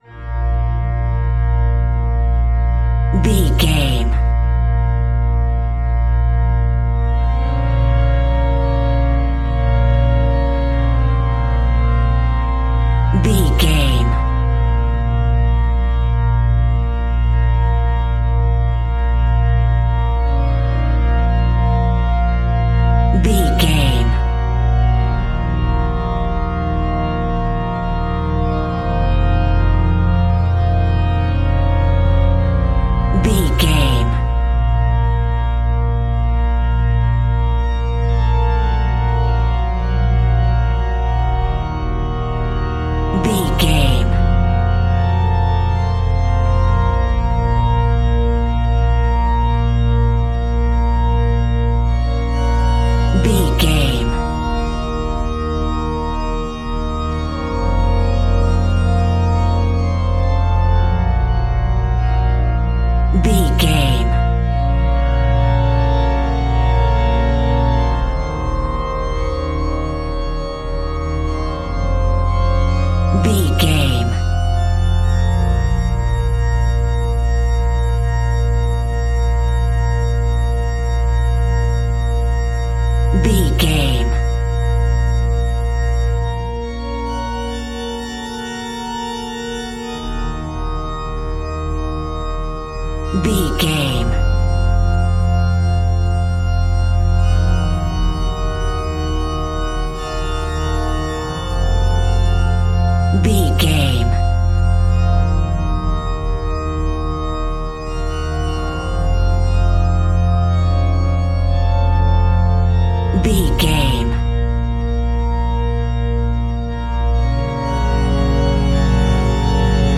In-crescendo
Aeolian/Minor
tension
ominous
dark
haunting
eerie
creepy
Church Organ